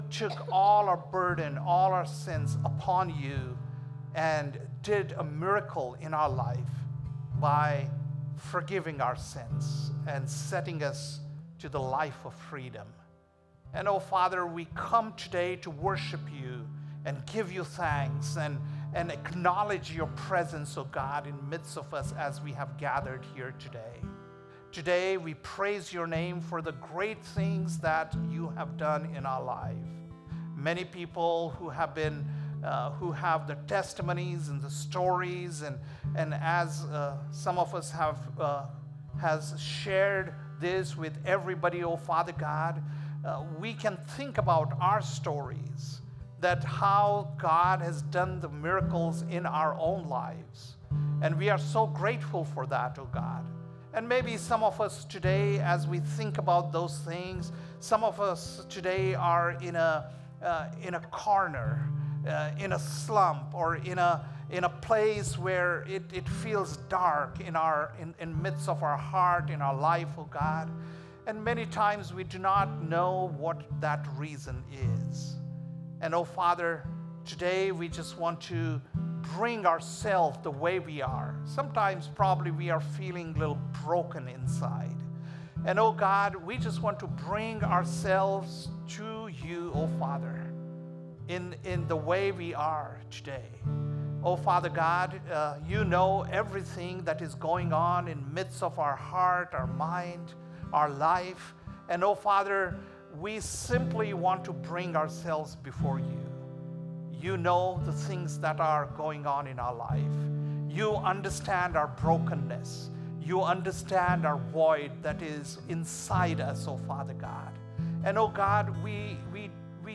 June 1st, 2025 - Sunday Service - Wasilla Lake Church